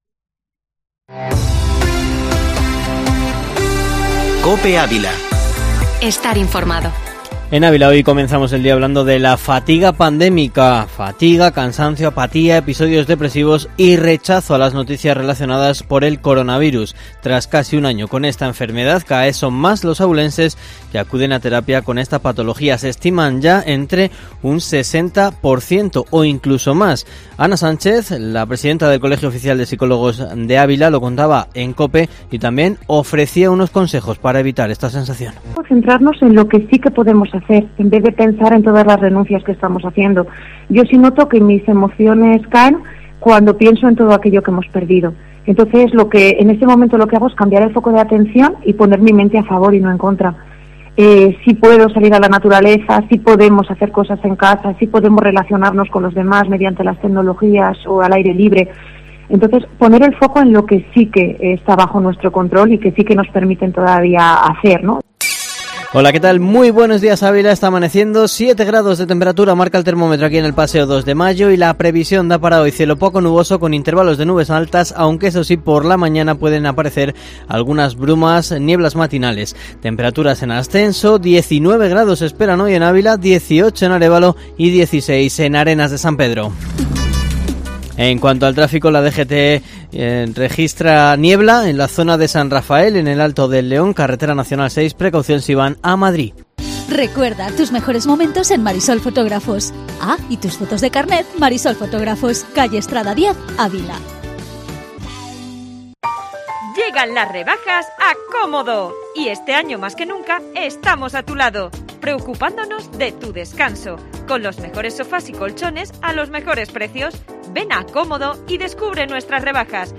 Informativo matinal Herrera en COPE Ávila 28/01/2021